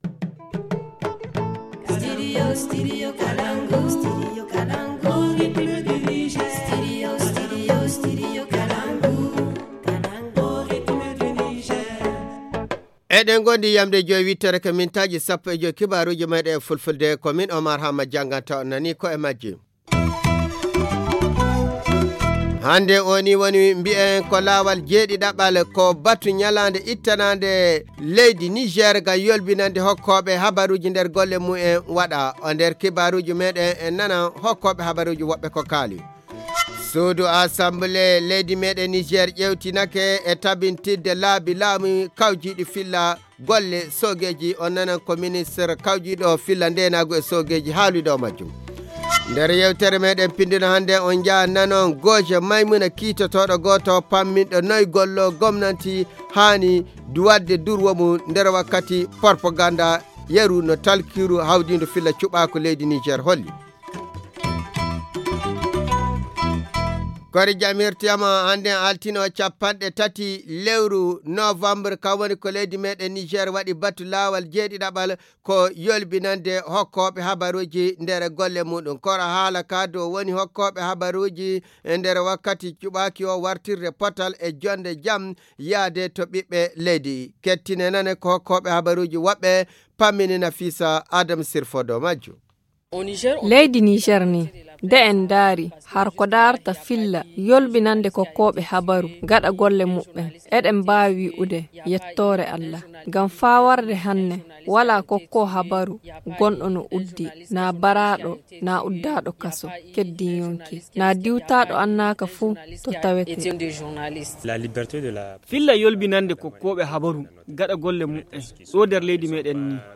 Le journal du 30 novembre 2020 - Studio Kalangou - Au rythme du Niger